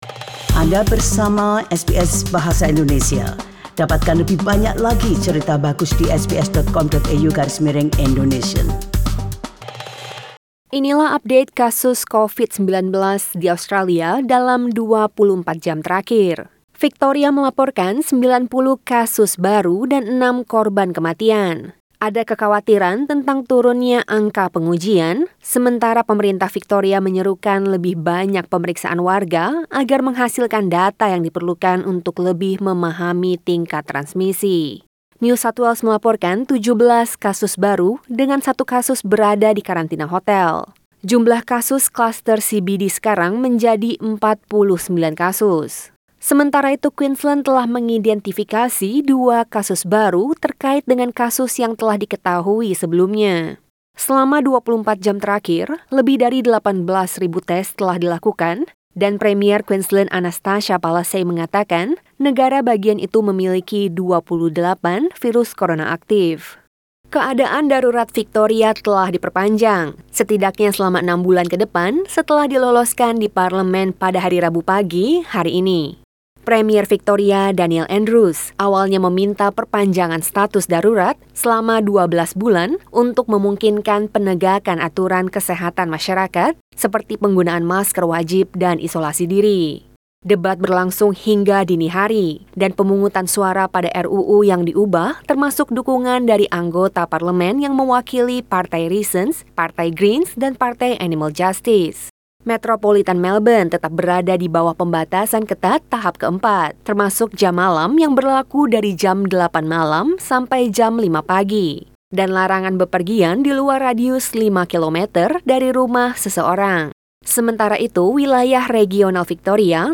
SBS Radio News in Bahasa Indonesia - 2 September 2020